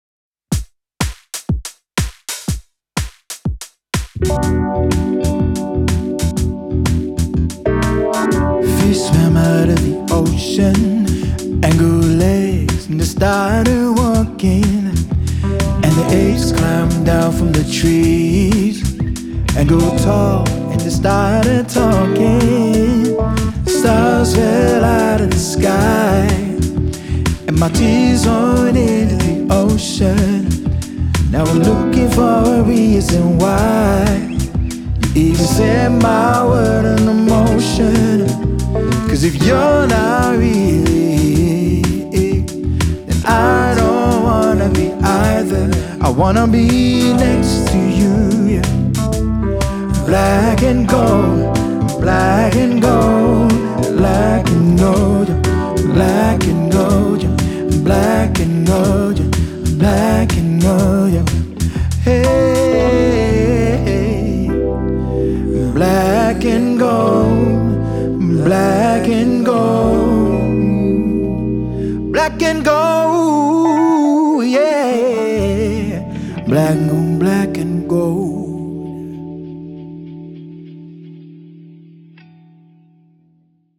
Keys | Guitar | Dual Vocals | Looping | DJ